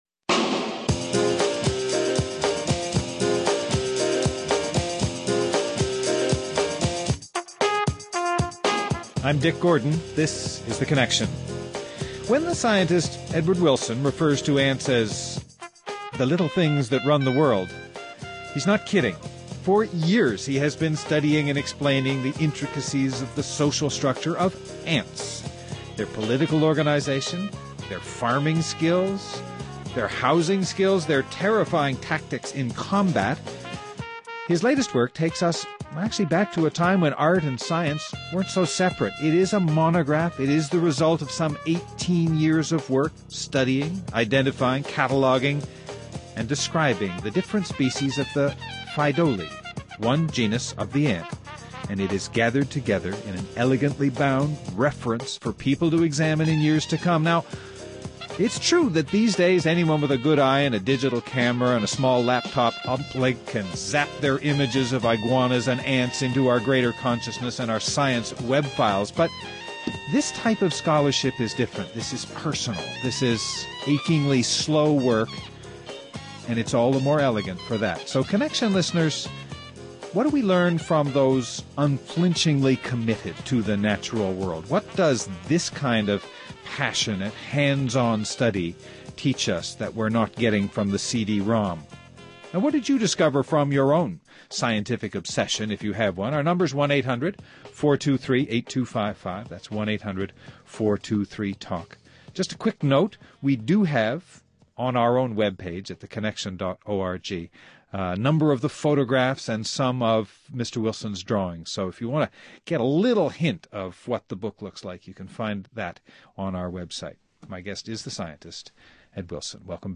Guests: EO Wilson, Pellegrino University Research Professor Emeritus at Harvard University.